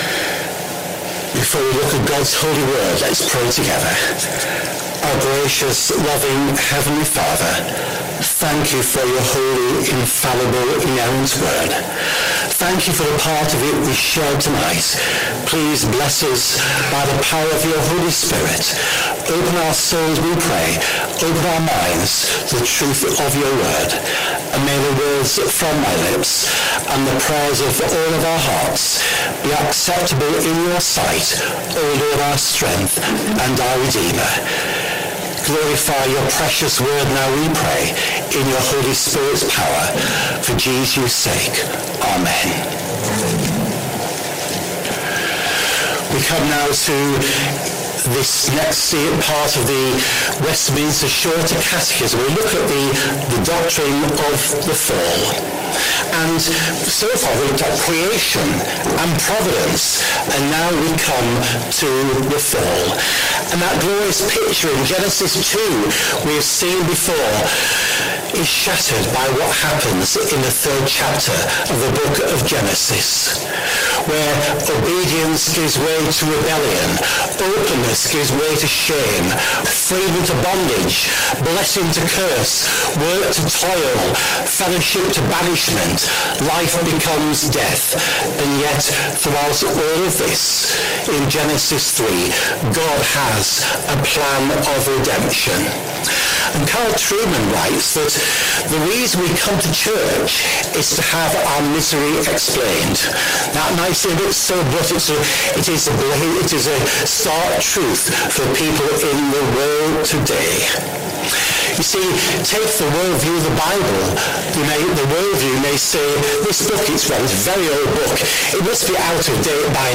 Sunday Evening Service Speaker